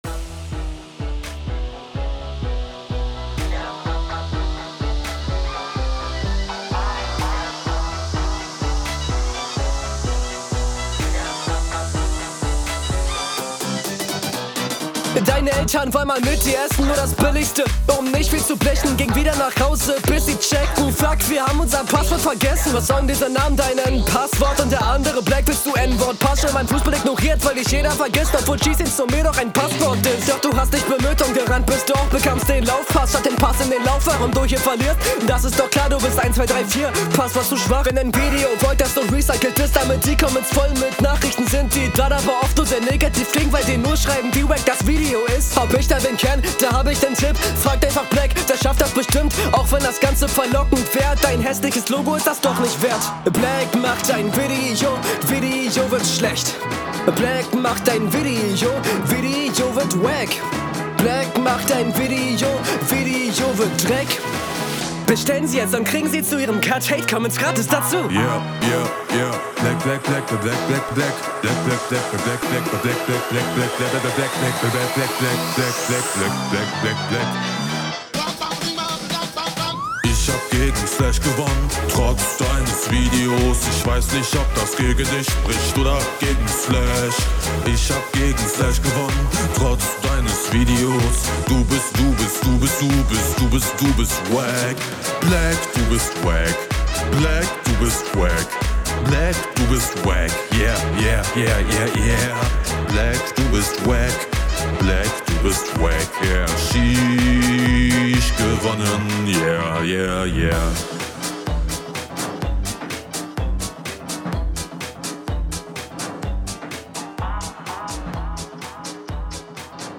Leider leidet die Delivery am hektischen Flow und so gehen punches unter.